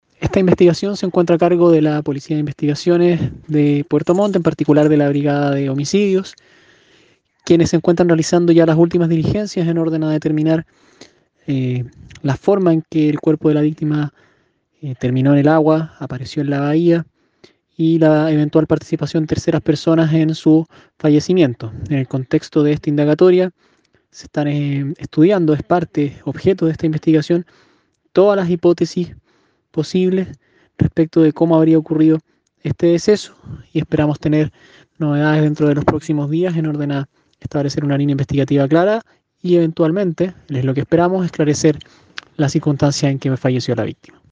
En tanto, desde la fiscalía de Puerto Montt el Fiscal Gonzalo Meneses, se refirió a la investigación que lleva adelante la Policía de Investigaciones de la capital regional.